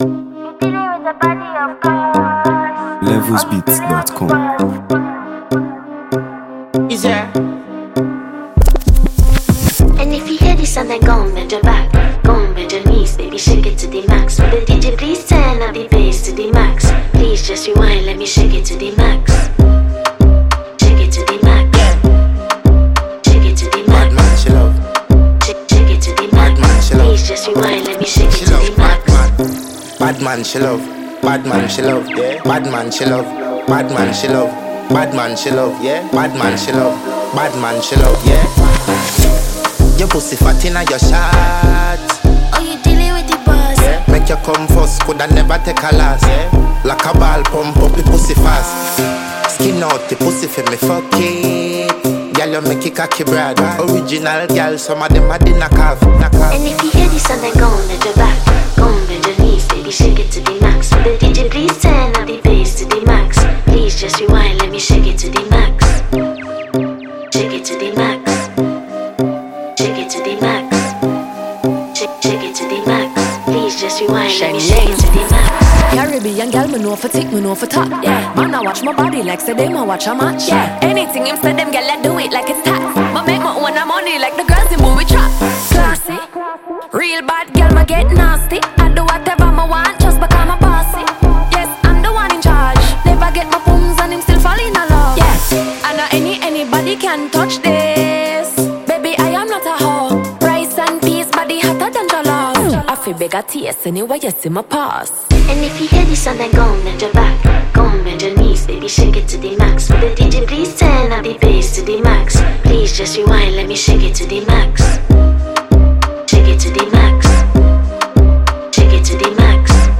Afro-fusion
This vibrant remix